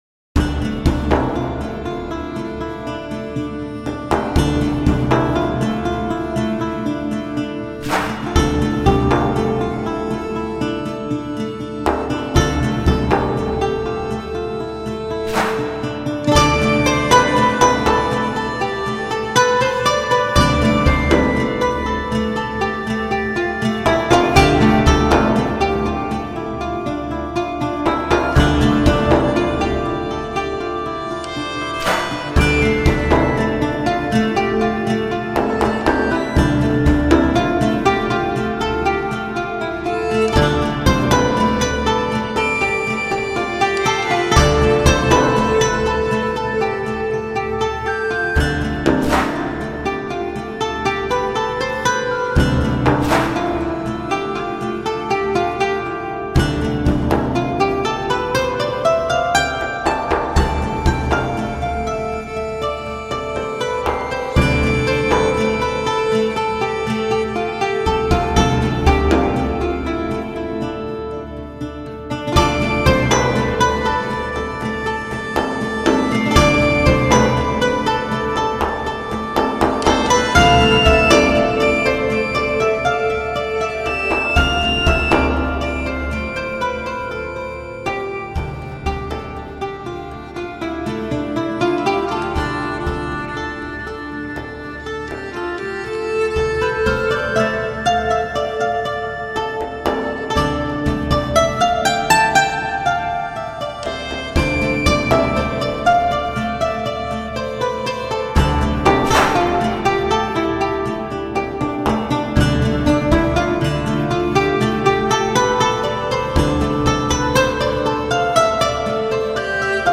۳. آمبینت (Ambient)